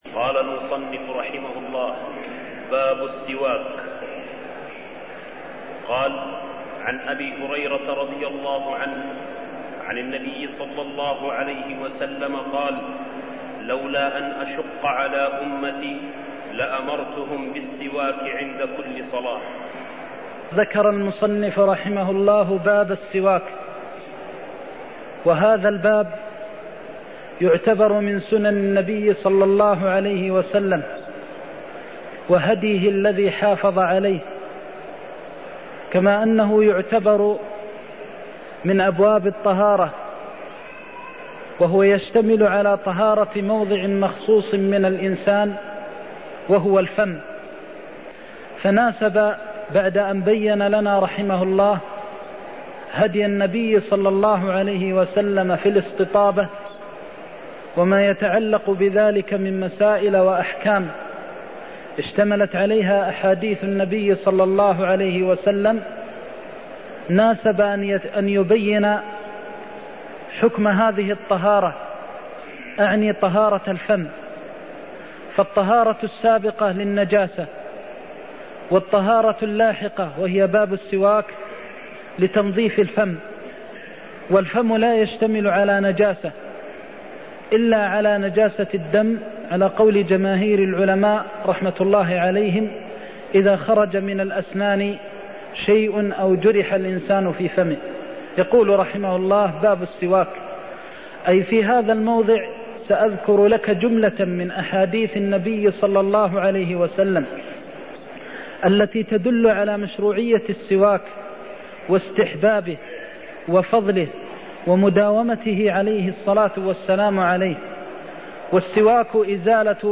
المكان: المسجد النبوي الشيخ: فضيلة الشيخ د. محمد بن محمد المختار فضيلة الشيخ د. محمد بن محمد المختار لولاأن أشق على أمتي لأمرتهم بالسواك (18)